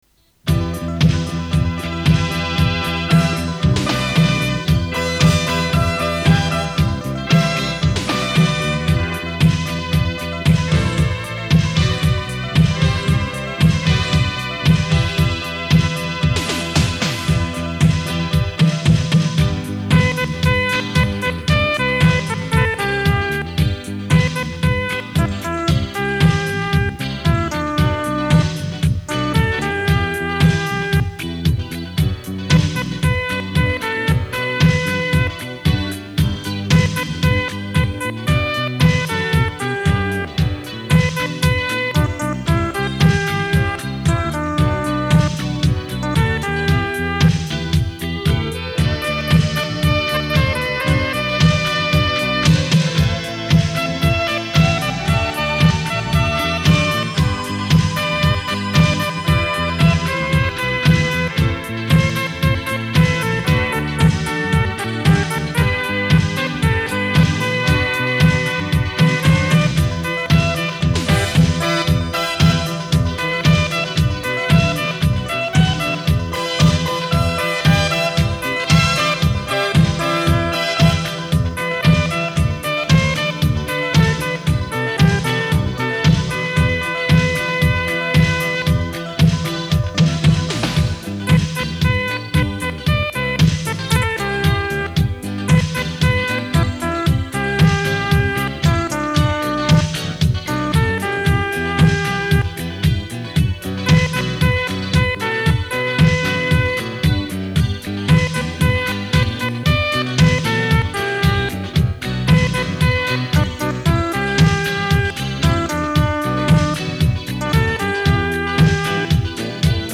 电声与人声的巧妙融合，勾画出惬意的
都市情景，听着这些浪漫抒情的音乐，